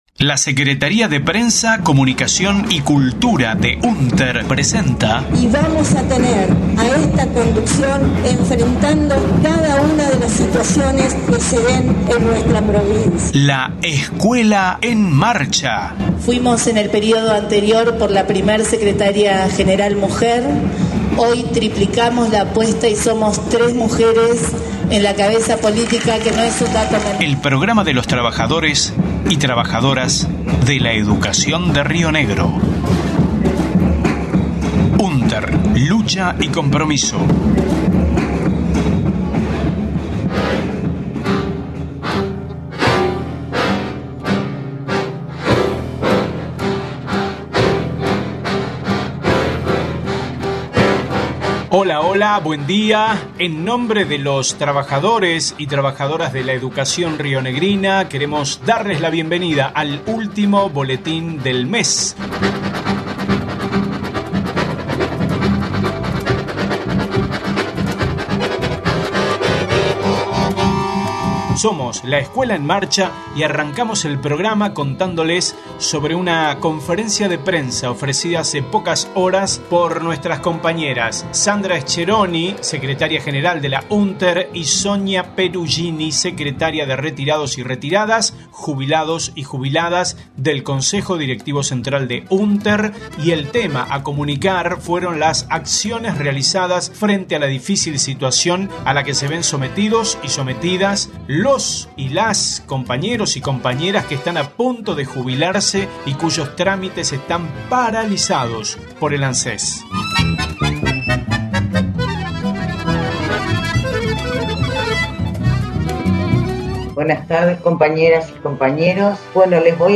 en conferencia realizada a través de las redes el 30/08/21 sobre demora de jubilaciones docentes y gestiones del sindicato.